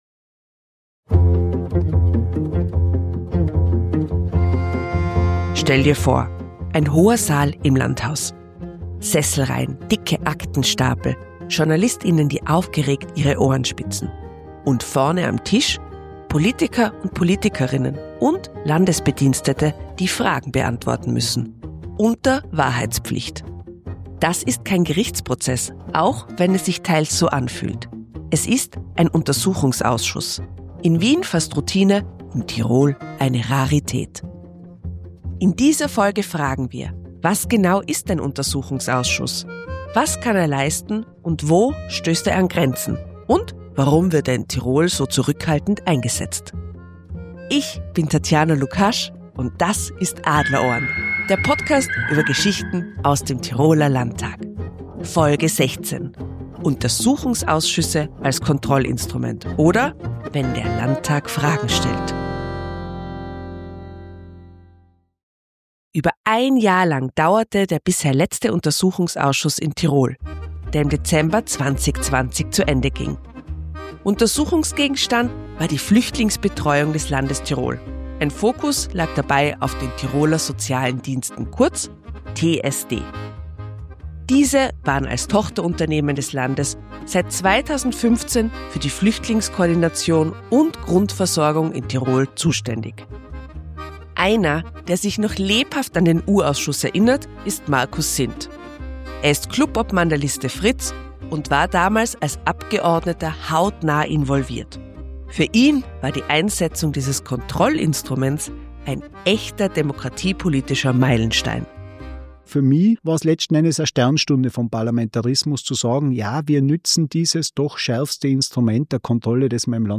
Beschreibung vor 7 Monaten In dieser Episode widmen wir uns den Untersuchungsausschüssen im Tiroler Landtag – einem zentralen Instrument politischer Kontrolle. Gemeinsam mit Abgeordneten und Expert:innen machen wir eine kleine Zeitreise ins Jahr 2019 zum bisher letzten Tiroler U-Ausschuss. Thema war damals die Flüchtlingsbetreuung, Stichwort Tiroler Soziale Dienste.